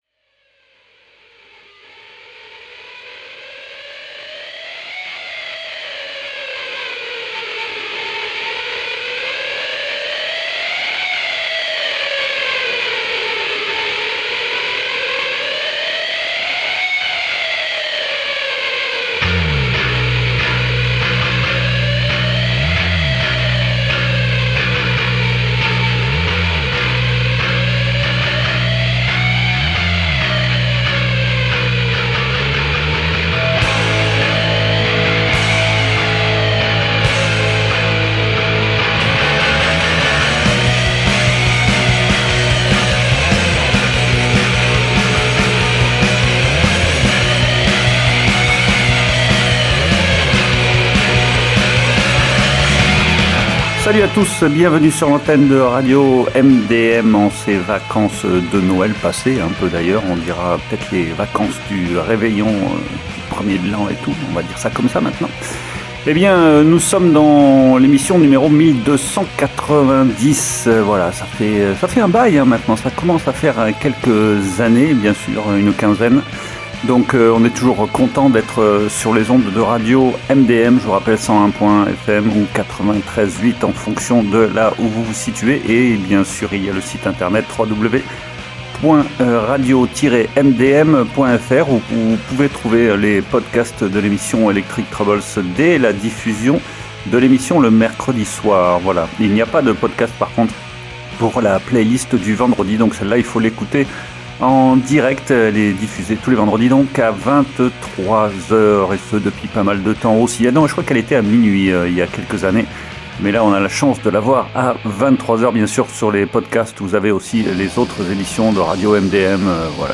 est une formation rock, tout simplement rock.